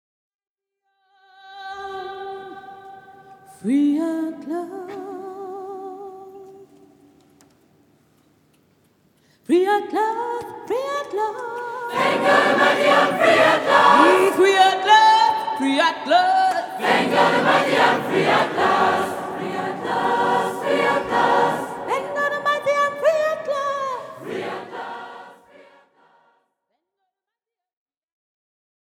en live à la cathédrale de Chartres